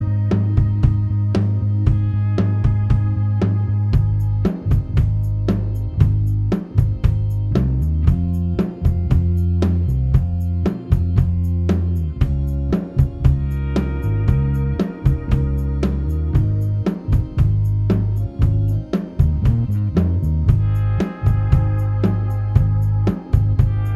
Minus Guitars Except Acoustics For Guitarists 3:04 Buy £1.50